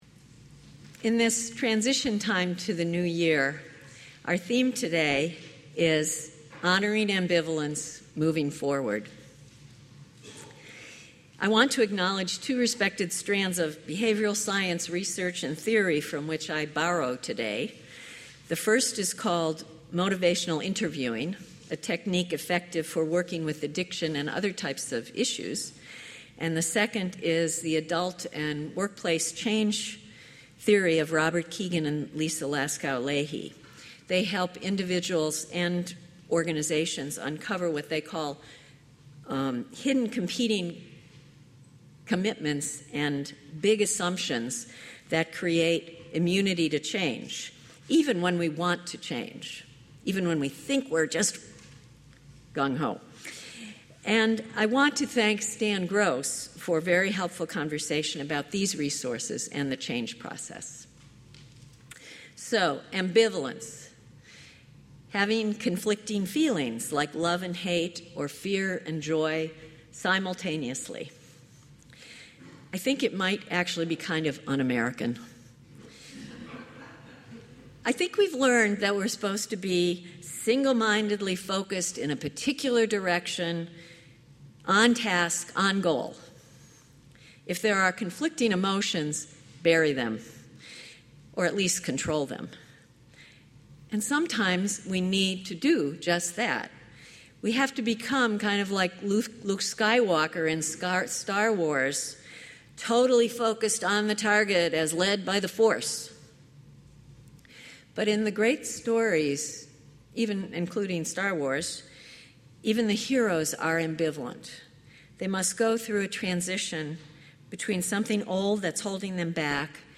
This service offers guided meditation to connect with our own assumptions about change and with what’s stirring now, time for quiet reflection and inner listening, and a ritual of release and commitment at this turning of the year.